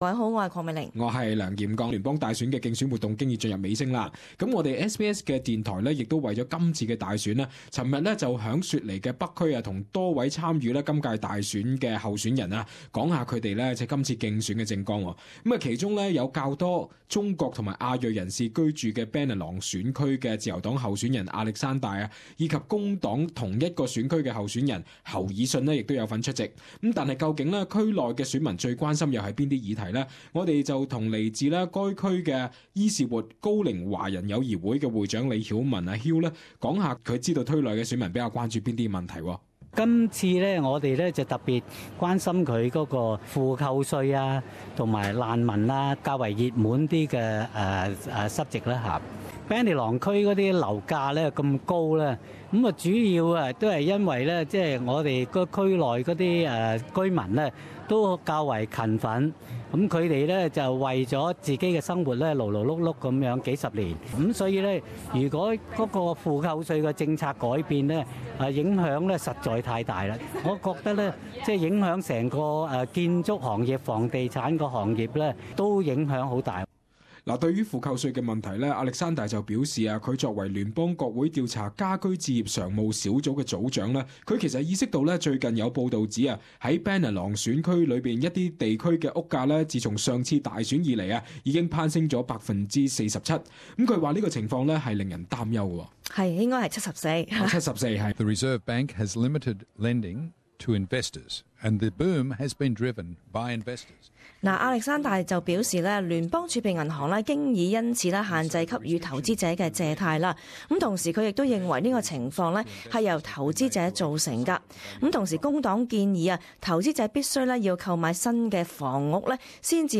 SBS Election series - Bennelong candidates interview Source: SBS